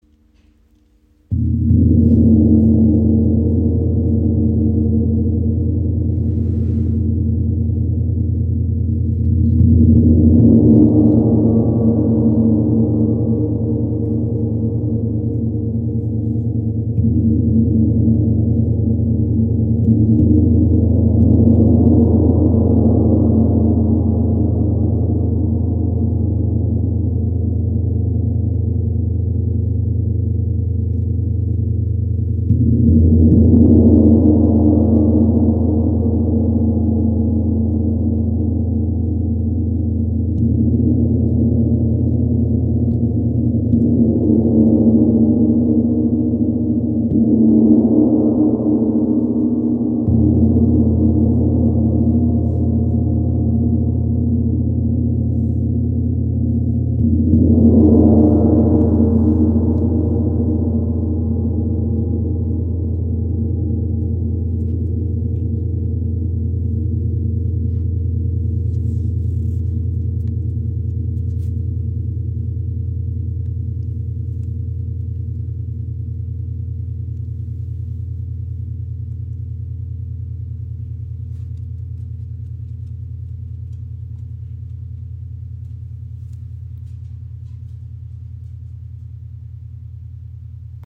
Feng Gong - Wind Gong Ø 90 cm im Raven-Spirit WebShop • Raven Spirit
Klangbeispiel
Dadurch können sich die Schwingungen leichter ausbreiten und der Gong kann durch Anschlagen in kräftige Vibration gebracht werden. Sein Klang kommt schnell ins Rauschen, weshalb er auch als Wind-Gong bezeichnet wird.
Beeindruckender Klangcharakter